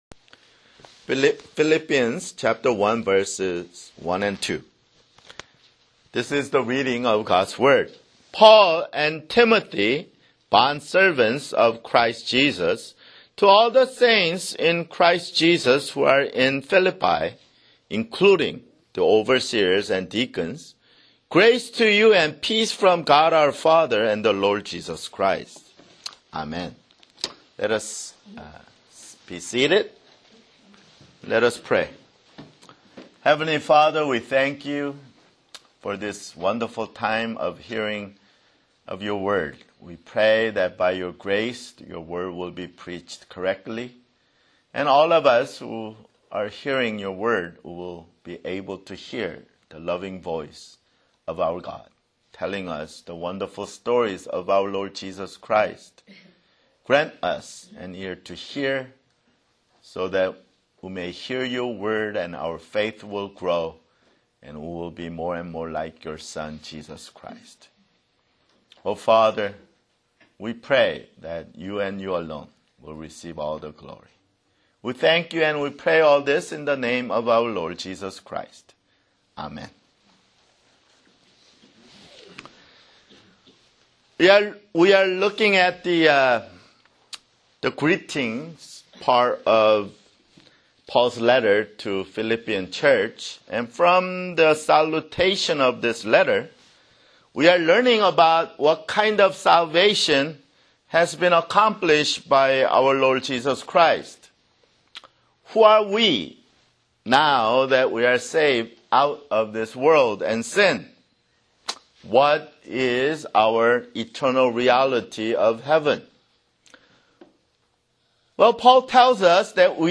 [Sermon] Philippians (5)